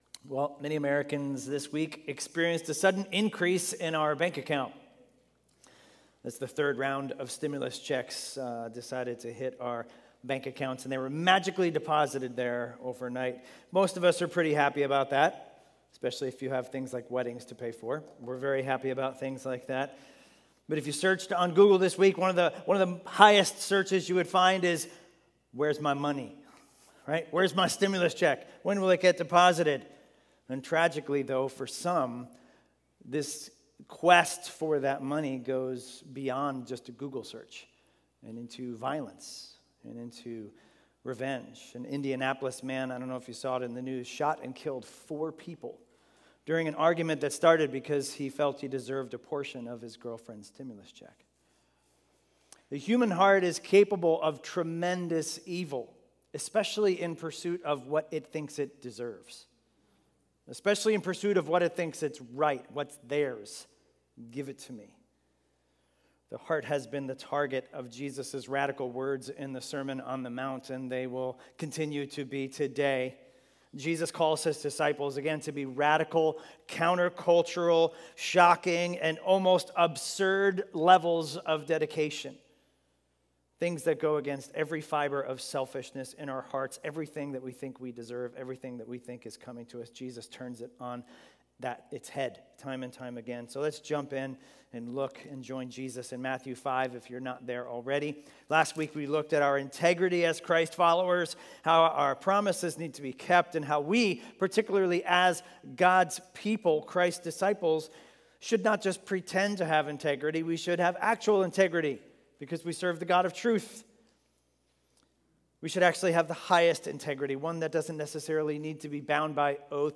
A message from the series "Revelation." In Revelation 1:1-8 we learn that Revelation shows us that God has a plan.